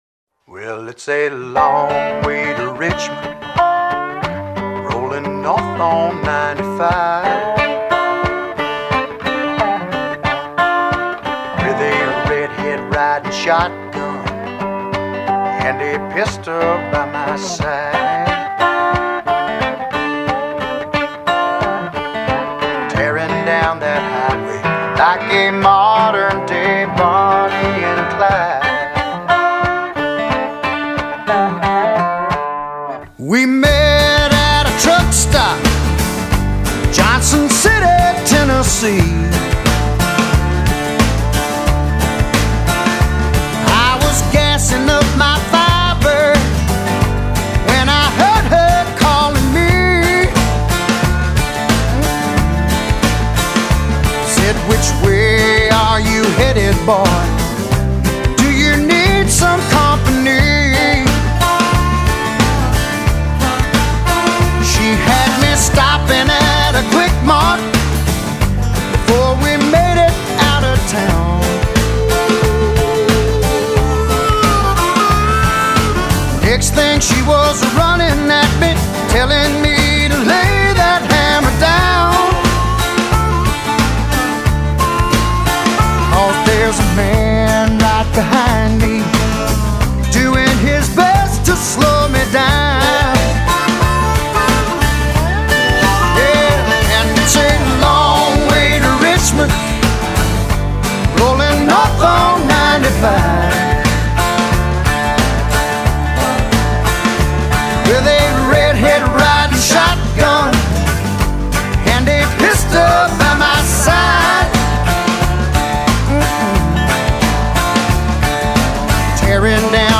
Tags: trucks country songs